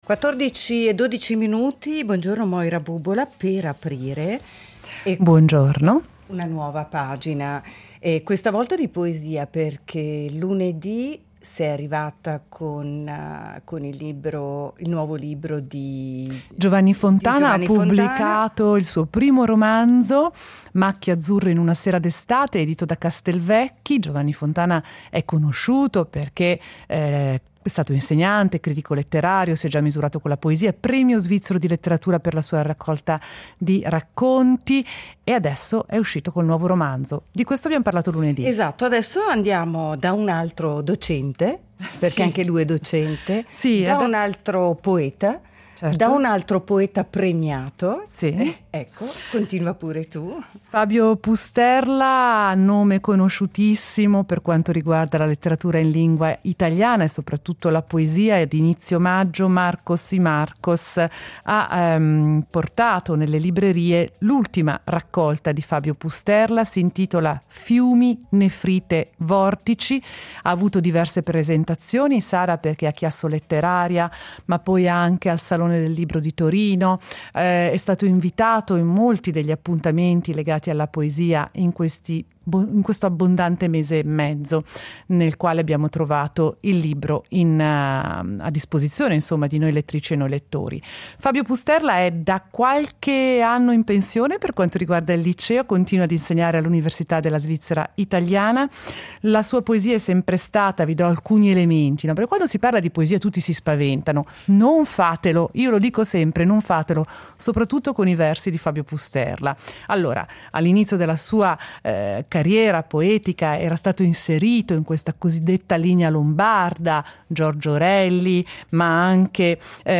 L’ha intervistato per noi